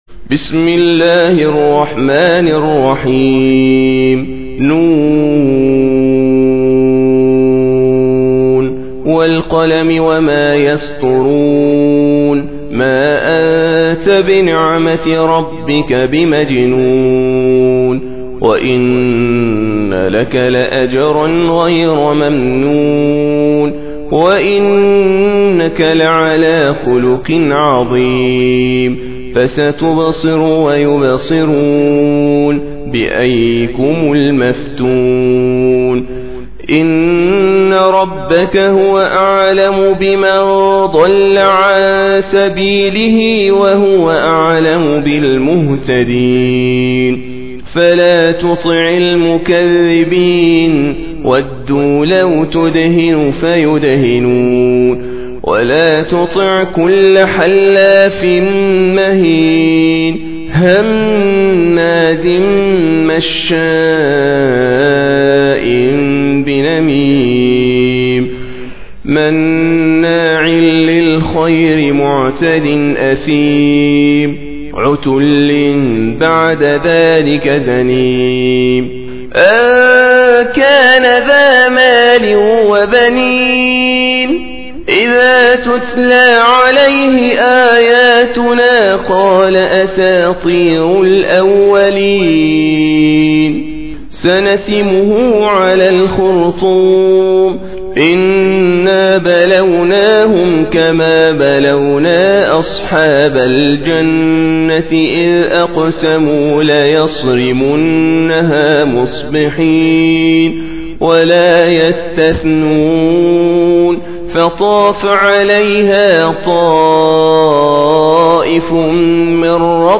Surah Sequence تتابع السورة Download Surah حمّل السورة Reciting Murattalah Audio for 68.